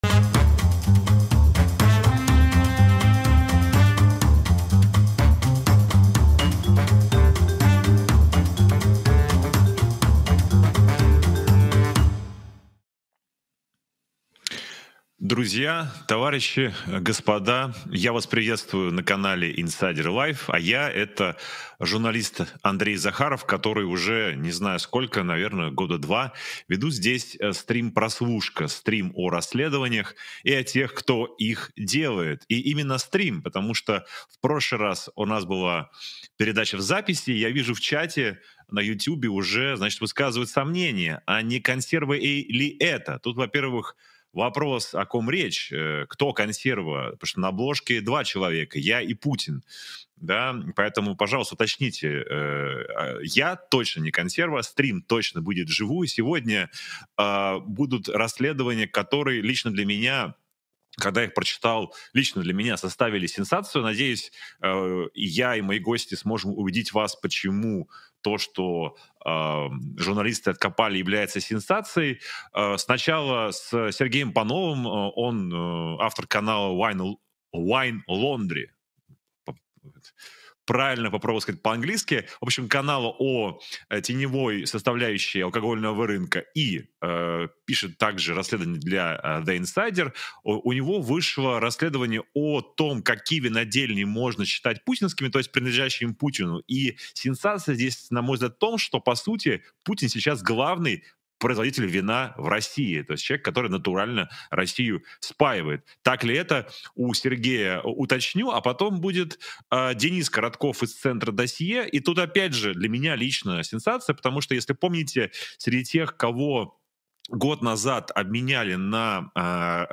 Эфир